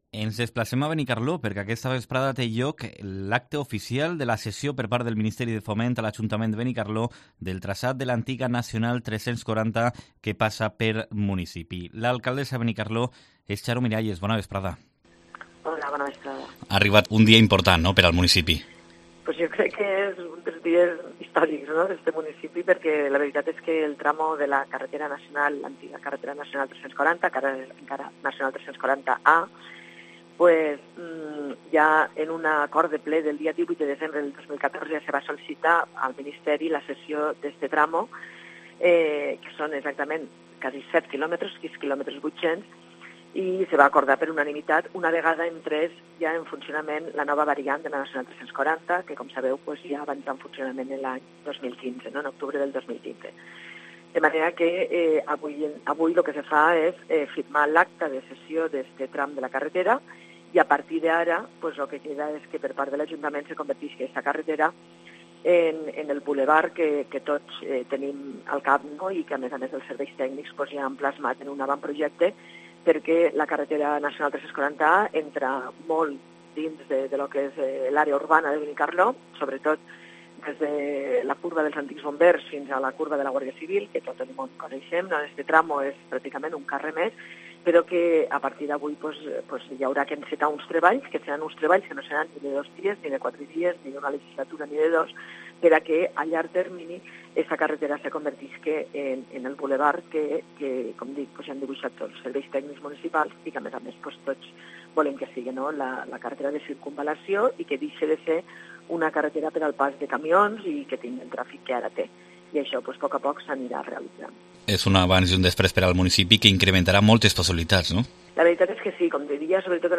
Entrevista a Xaro Miralles (alcaldessa de Benicarló)